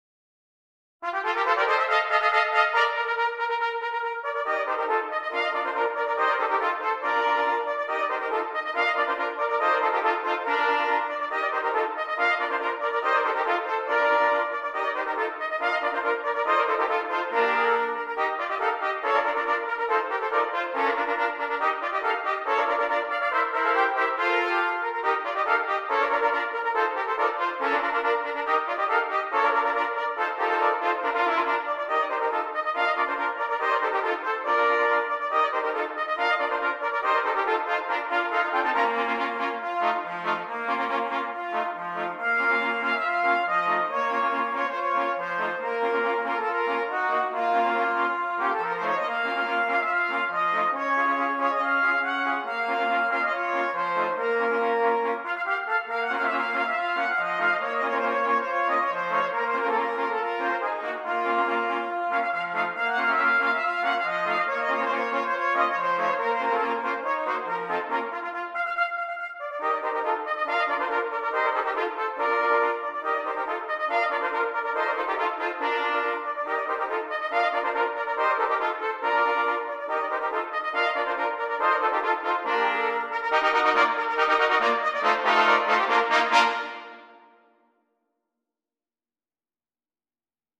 5 Trumpets
It is written for 5 trumpets.